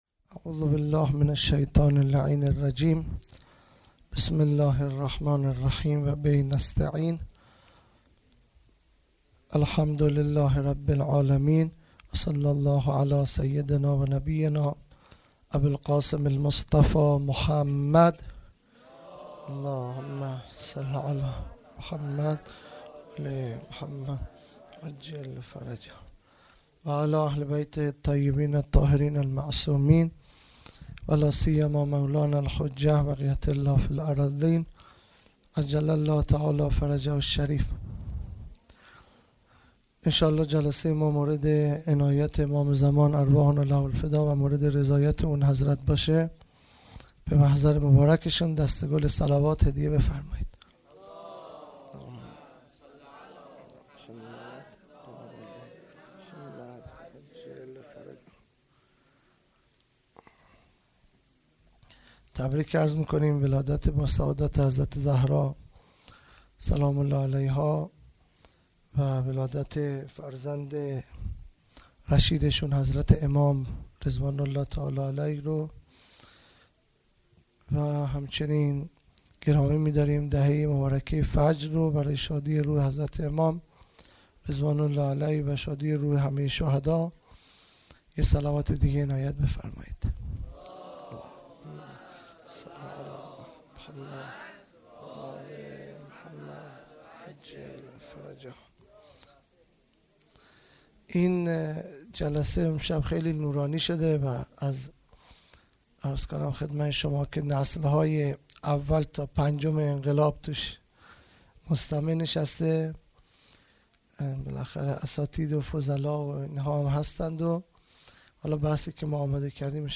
خیمه گاه - هیئت بچه های فاطمه (س) - سخنرانی|پنج شنبه ۱۶ بهمن ۹۹